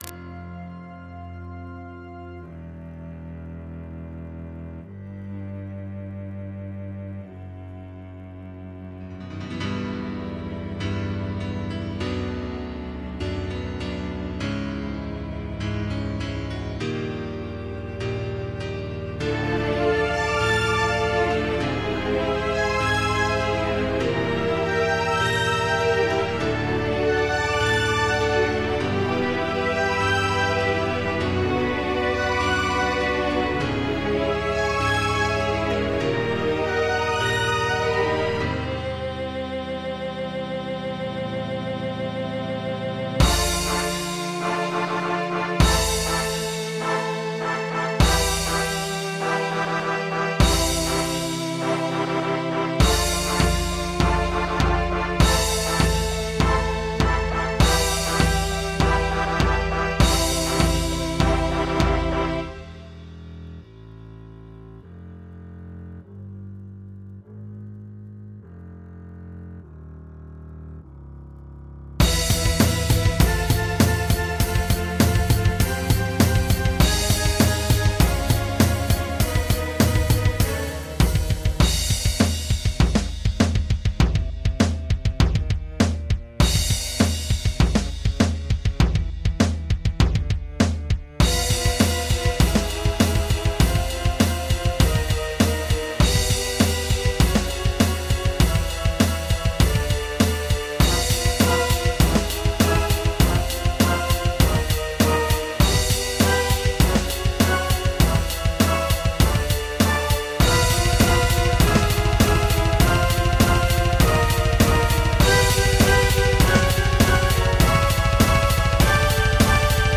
symphonic metal track